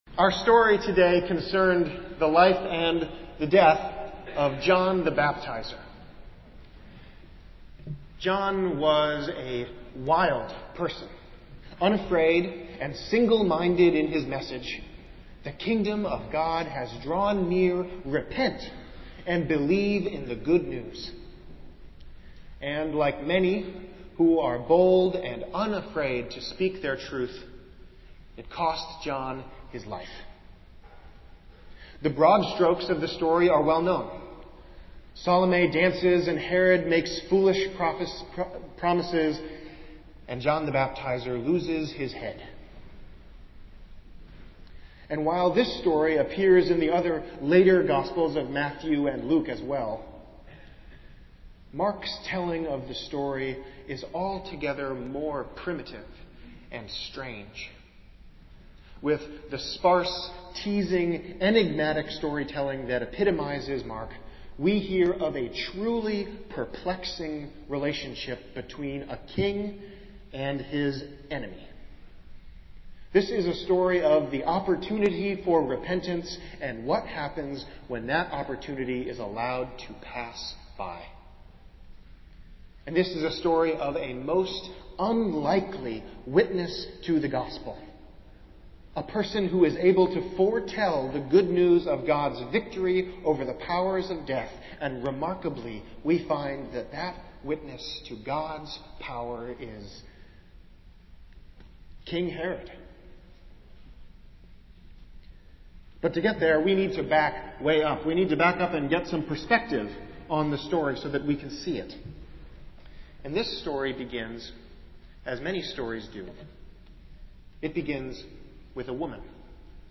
Festival Worship - Fourth Sunday in Lent